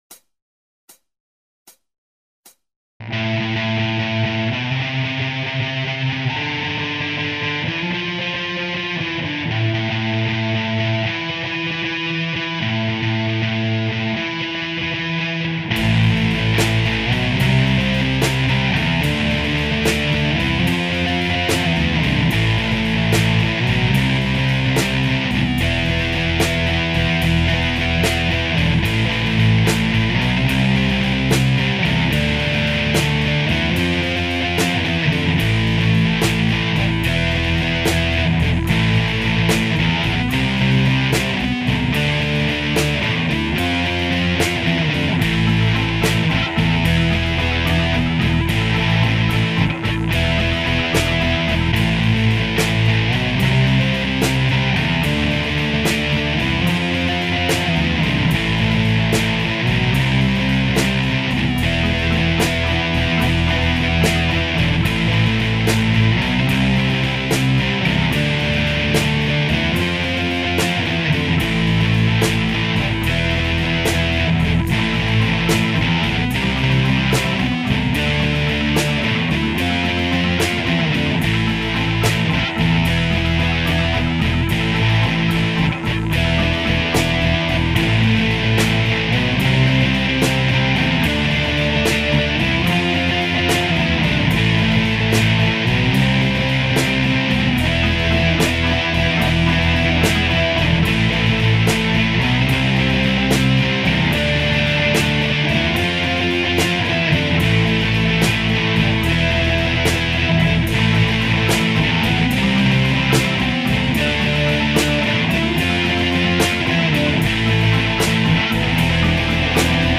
Also, its an old folk song and not a The Animals song ( as I always believed) Anyway, thought I'd do my own version. All the guitar work is me, the rest is backing track I downloaded.